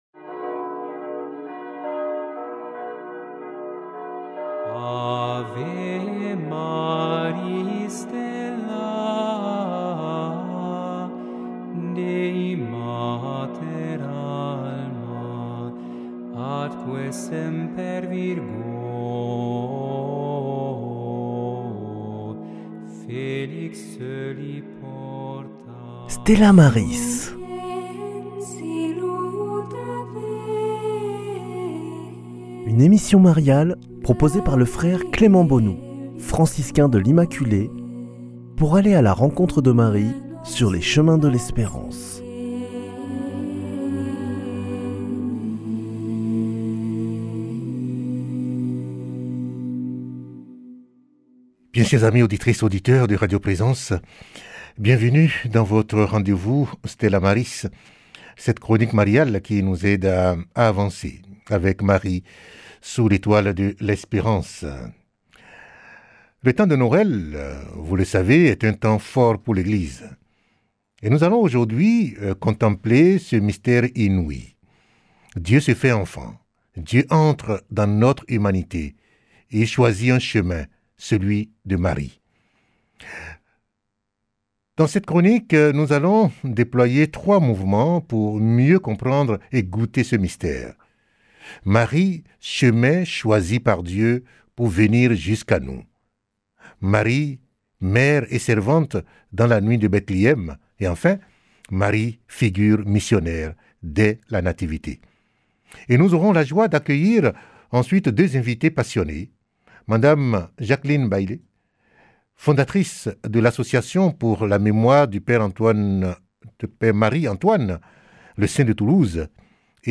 Dans cette chronique spéciale de Noël